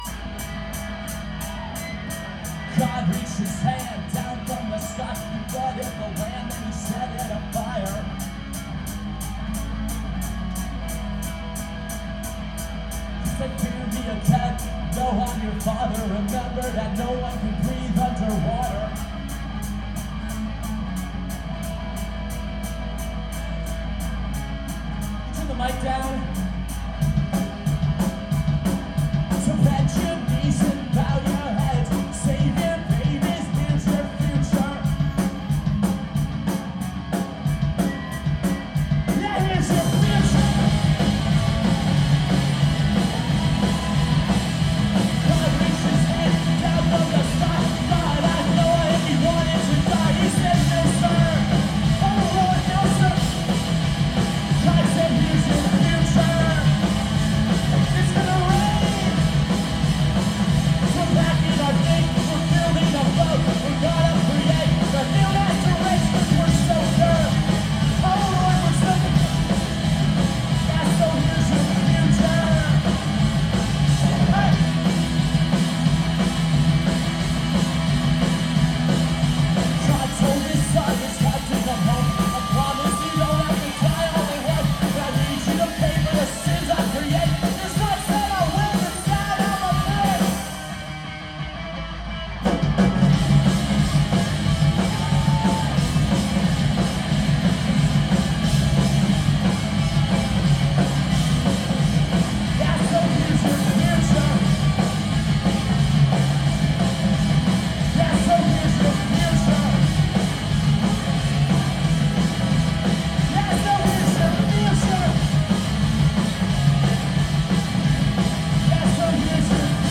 2006-10-20 Neumo’s – Seattle, WA